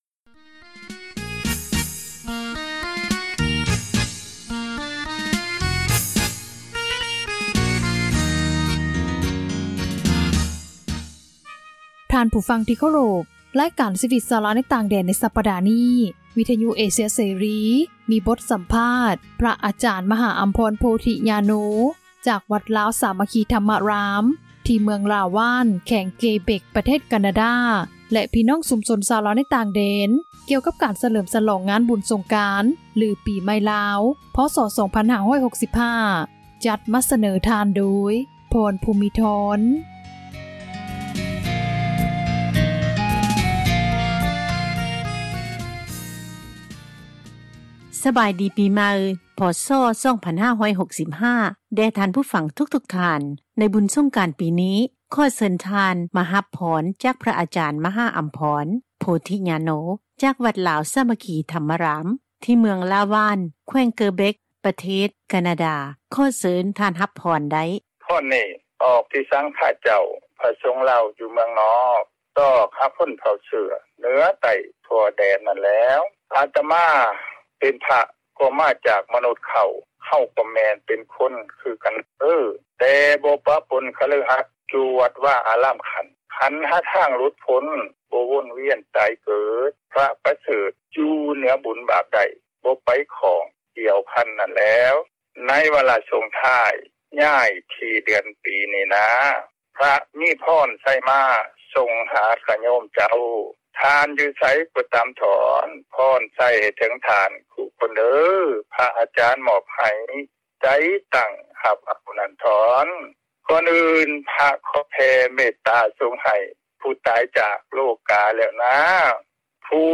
ມີບົດສັມພາດ